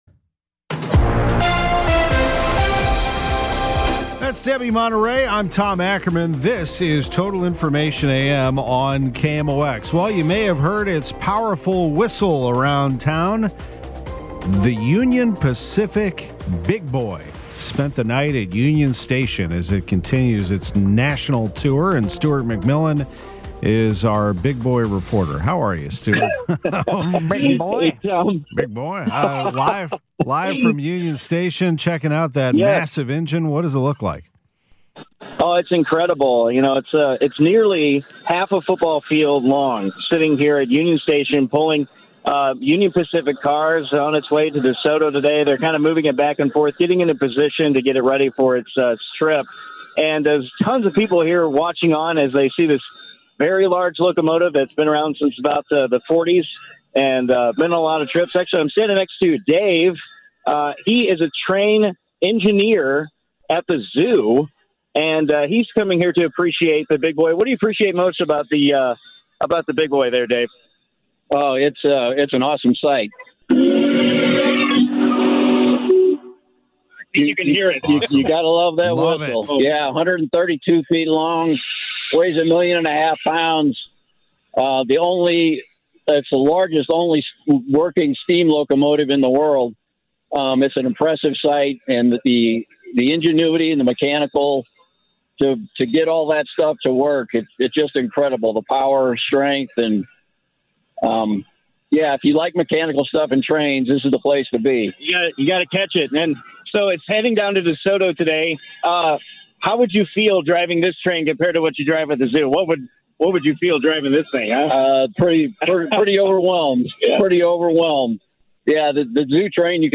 Live Report: Union Pacific's "Big Boy" steam locomotive continues midwest tour